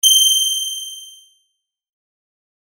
キーン
/ F｜演出・アニメ・心理 / F-15 ｜ワンポイント キラーン_キラキラ_ok
カーーーン